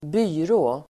Uttal: [²b'y:rå]